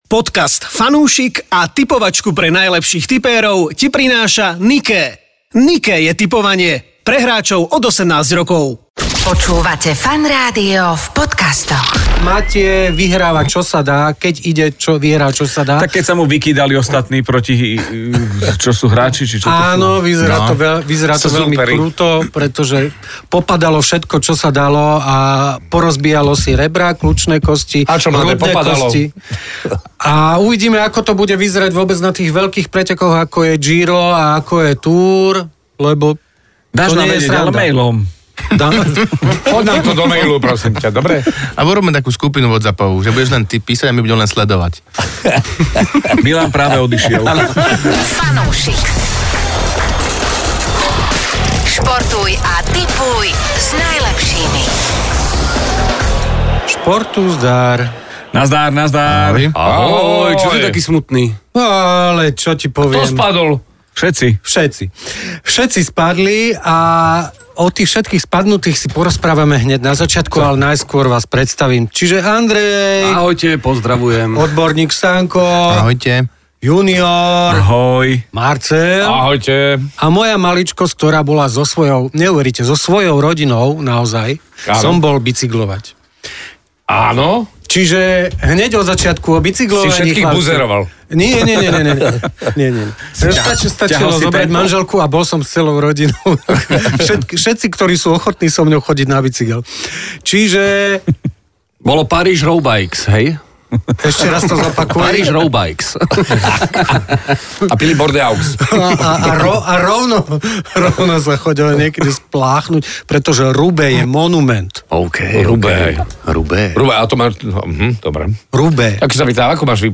Daj si fanúšikovskú debatku o športe a tipovaní.